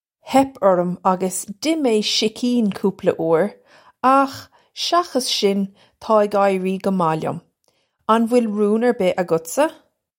Hep orrum uggus dih may shikeen koopla oo-ir akh shakhuss shin, taw ig eye-ree guh mah lyum. Un vwill roon urr bih uggutsa?
This is an approximate phonetic pronunciation of the phrase.